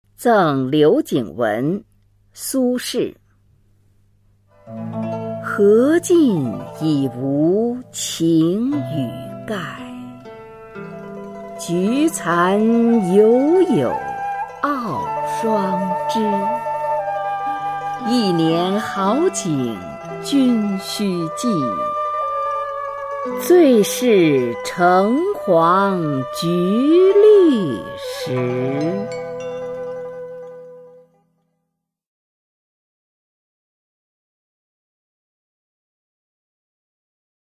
[宋代诗词诵读]苏轼-赠刘景文 宋词朗诵